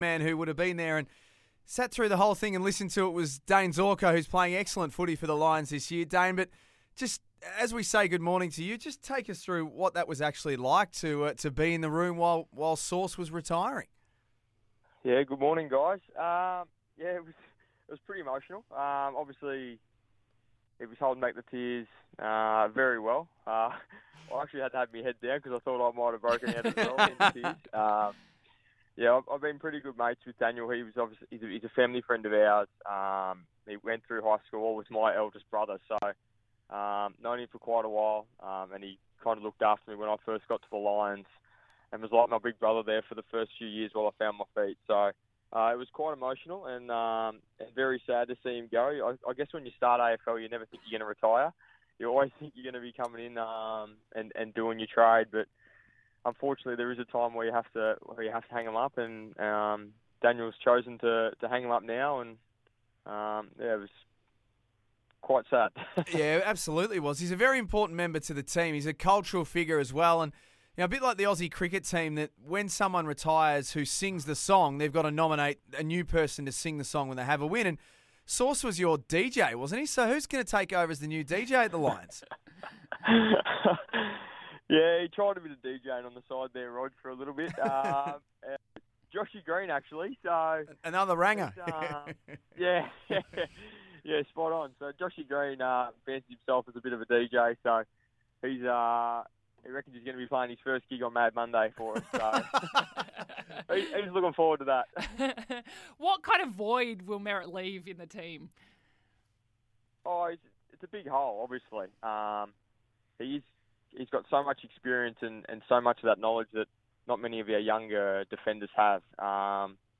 Dayne Zorko spoke to the guys at EON Sports this morning.